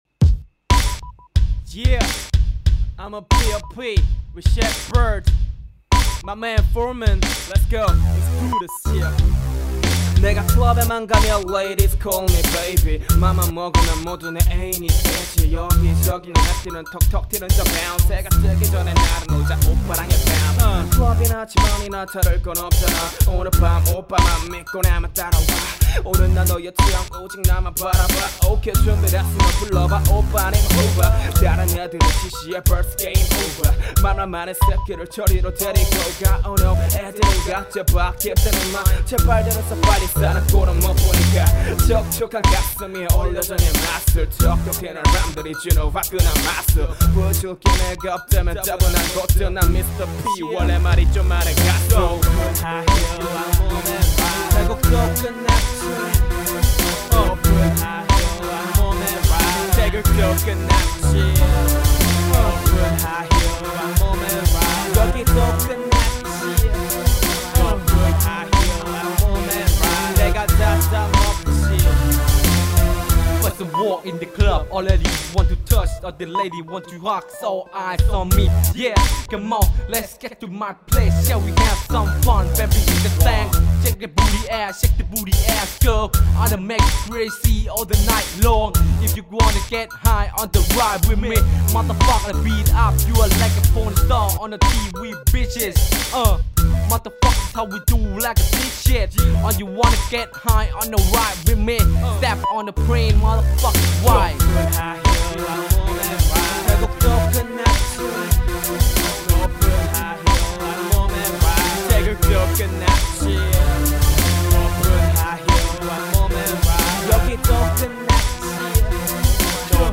• [REMIX.]
마지막벌스하는 형 랩이 참 좋더라구요 ㅎ 태국어라 처음들으실땐 약간 이상하겠지만
너무 한 그루브? 플로우? 로 간다는게 느껴져요ㅎㅎ
그런데 전체적으로 부드럽네요~
그루브함이 일품인 랩이네요 ^^